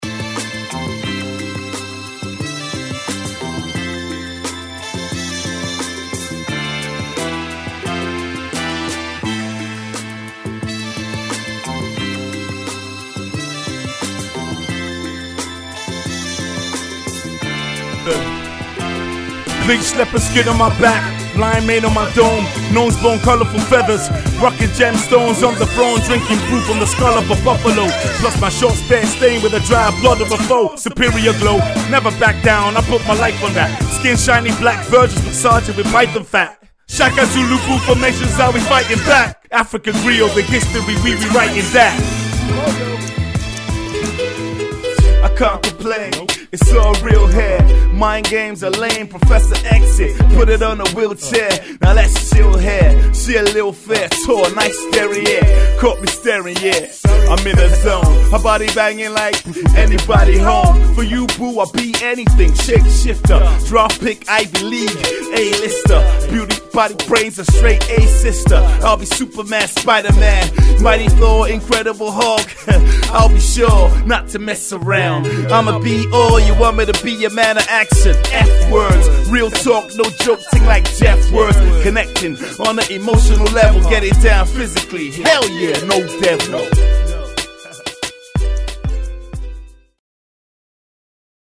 Hip-Hop
a Medley of Two instrumentals